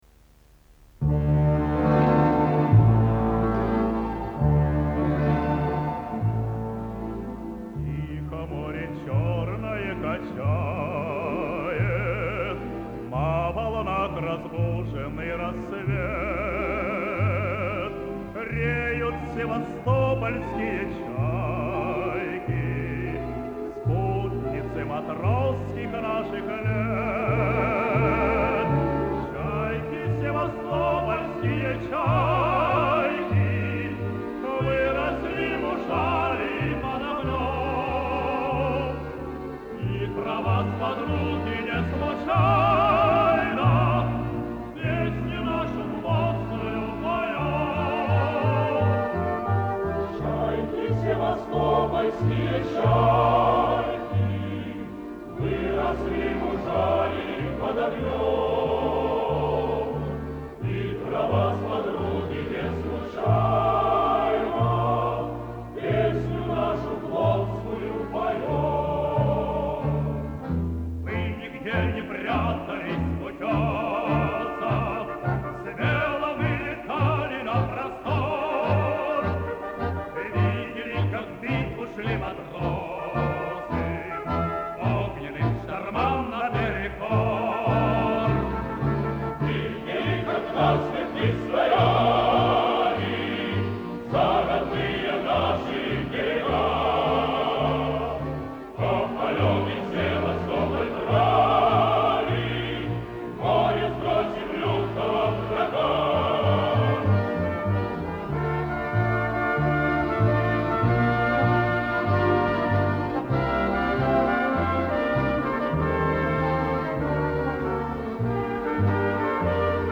Морские песни